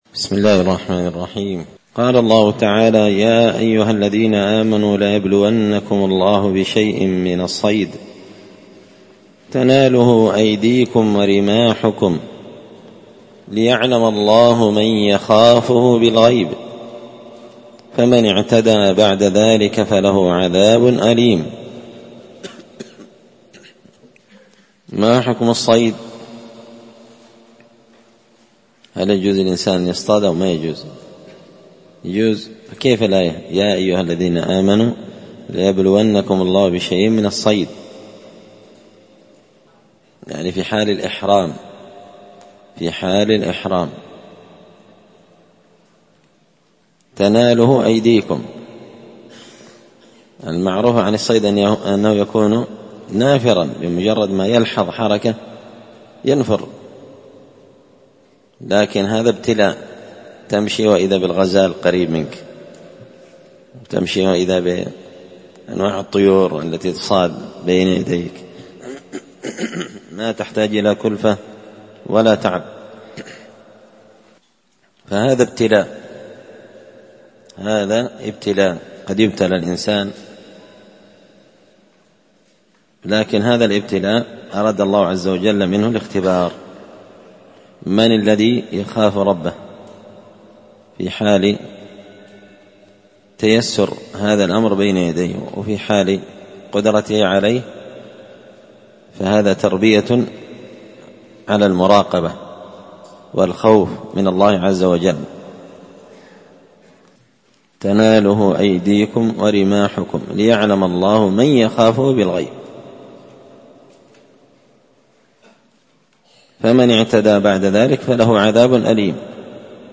مختصر تفسير الإمام البغوي رحمه الله الدرس 281